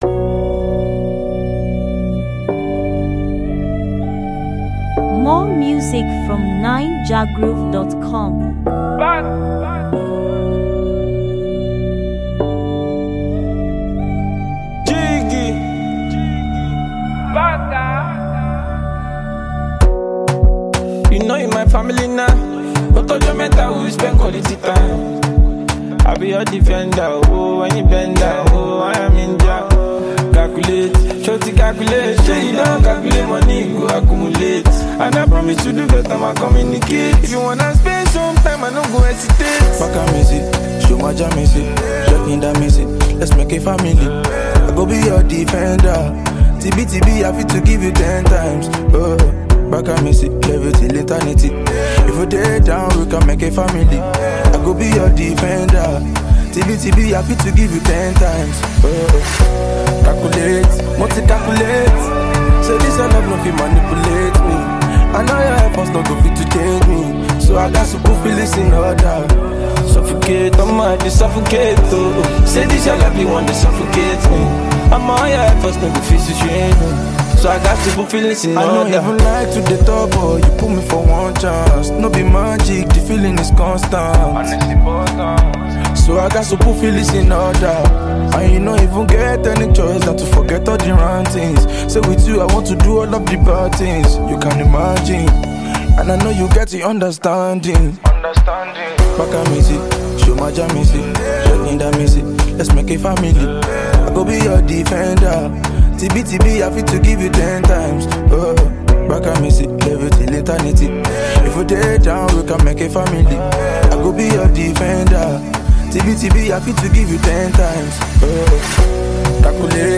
Latest, Naija-music
With its catchy beats and captivating lyrics